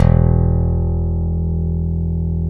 Index of /90_sSampleCDs/Roland LCDP02 Guitar and Bass/BS _Rock Bass/BS _Stretch Bass